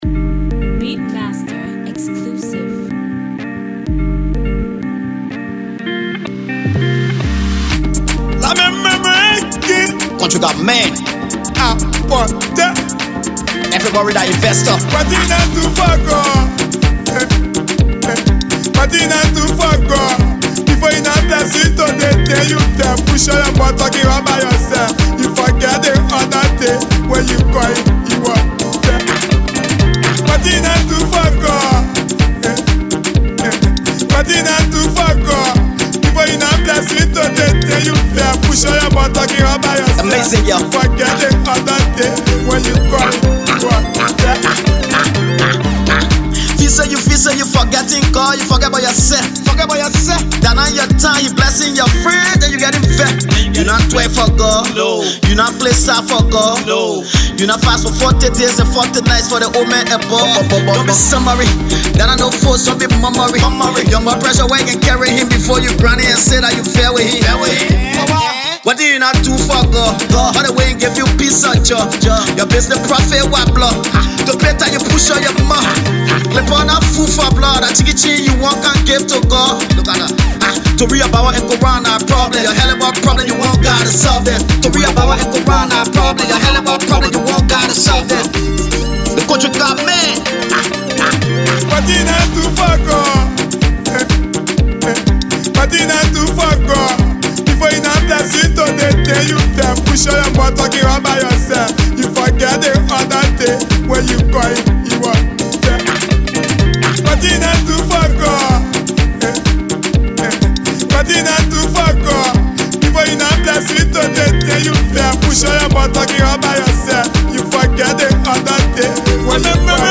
/ Hip-Co, Colloquial, Liberian Music / By